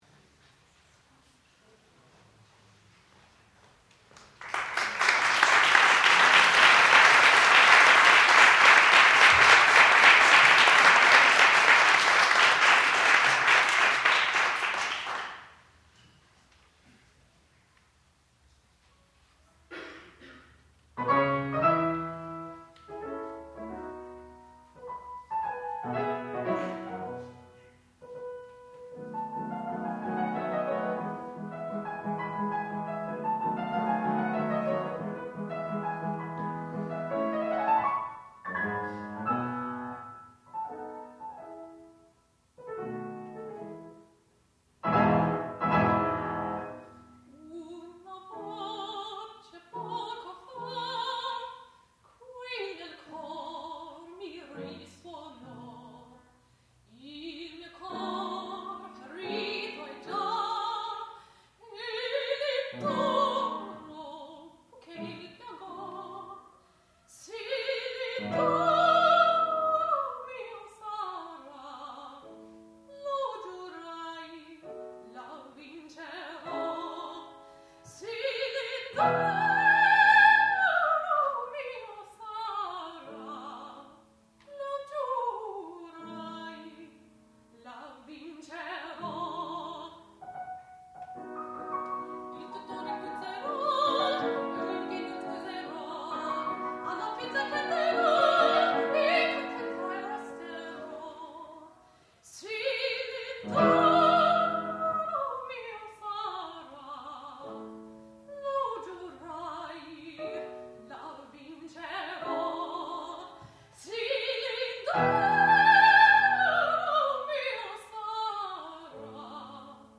sound recording-musical
classical music
piano
Junior Recital
mezzo-soprano